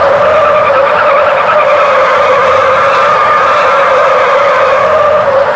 road_skid_ext.wav